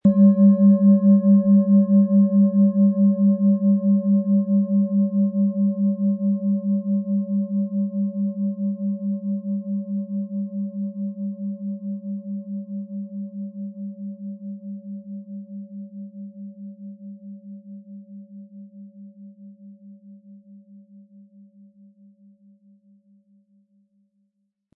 Antike Pluto-Planetenschale Ø 15,4 cm im Sound-Spirit Shop | Seit 1993
Sanfter, tragender Klang: Ideal für Meditation, Entspannung und emotionale Klarheit
PlanetentonPluto & DNA (Höchster Ton)
MaterialBronze